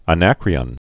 (ə-năkrē-ən) 563?-478?